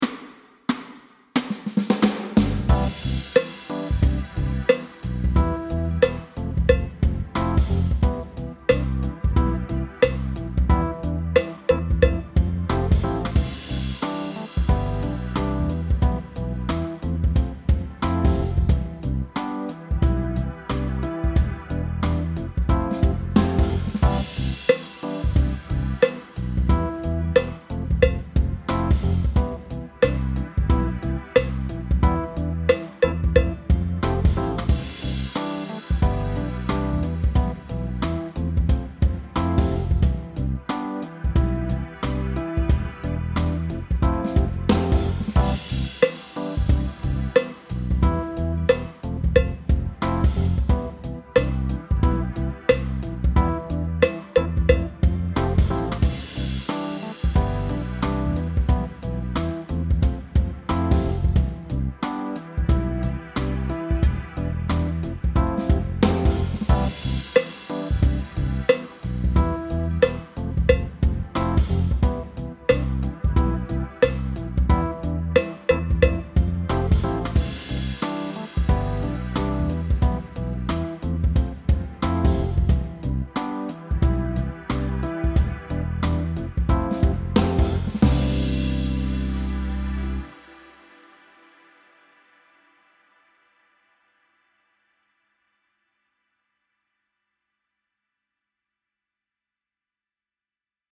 Lyt til rytmen i takt 1-4. Gentag/imitér rytmen i takt 5-8.
Elementer i dette kapitel er: 1/4´- og 1/8´dels noder og pauser.
Tonen “C” passer til alle opgaverne.